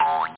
boing.mp3